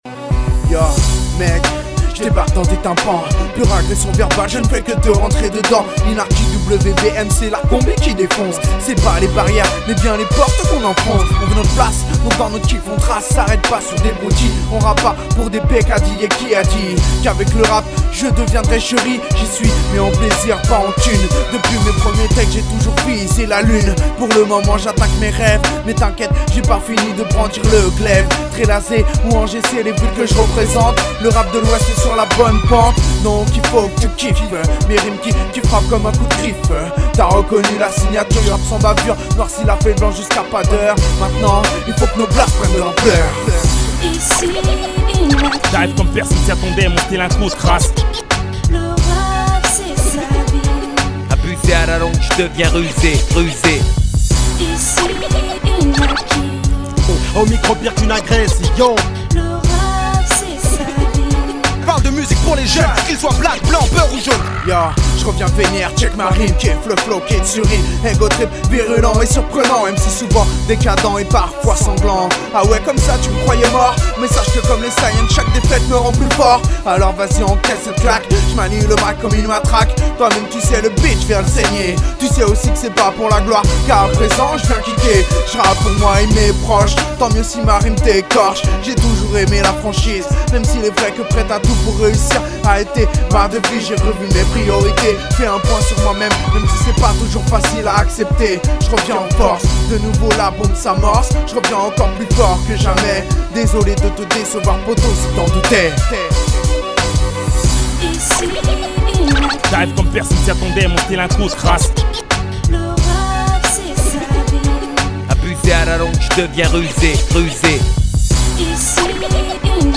rappeur Trélazéen